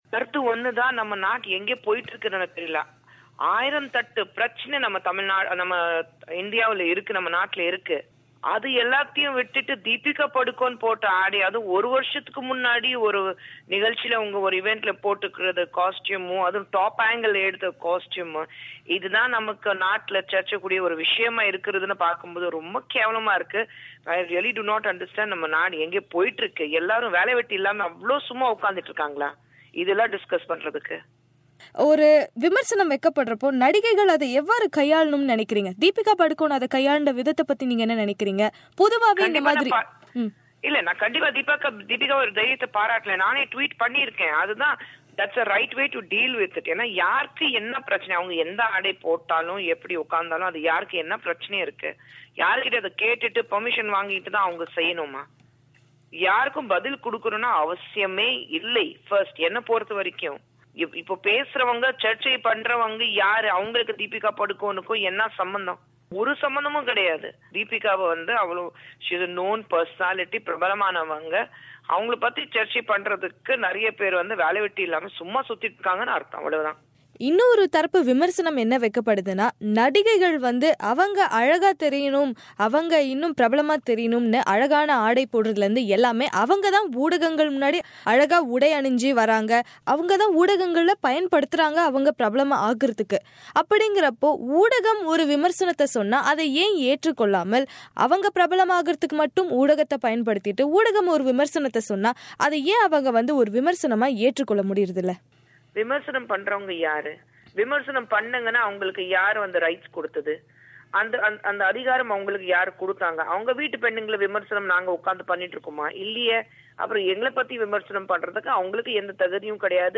குஷ்புவின் பேட்டியை நேயர்கள் இங்கே கேட்கலாம்.